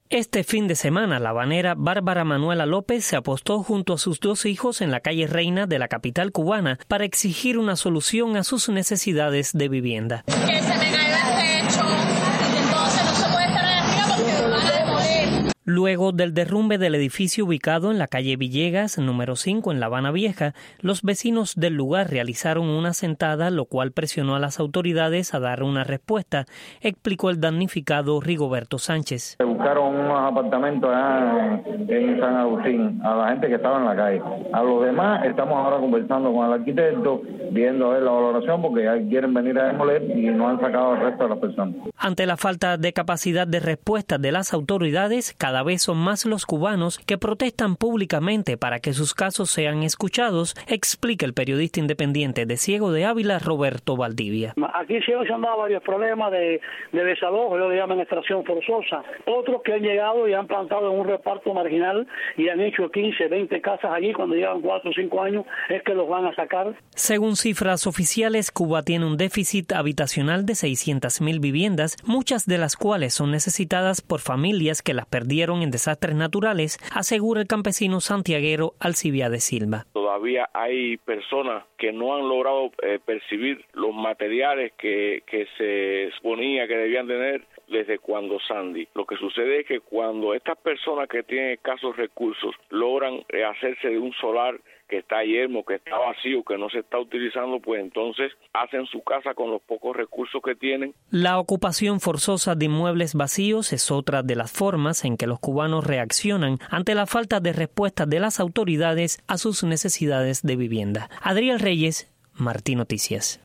Testimonios de cubanos sobre el incremento de las protestas en Cuba.